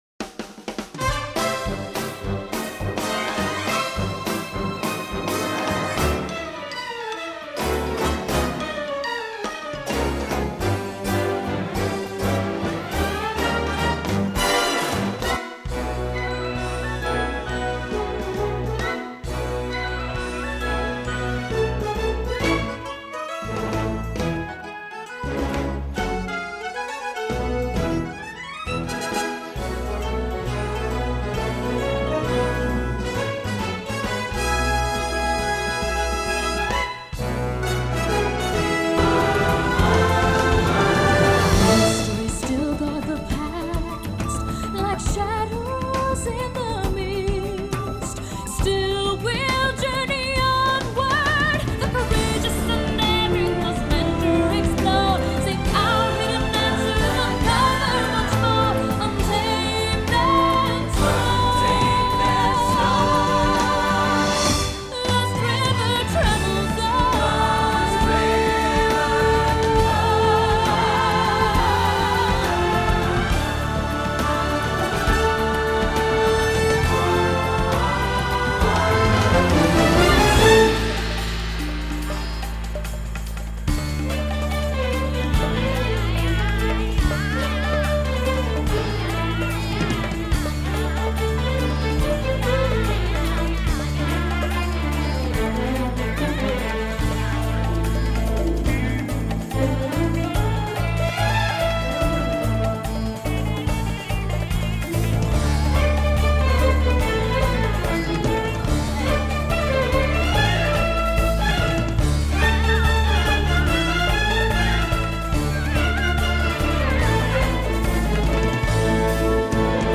recorded at Paramount studios with a 120 piece orchestra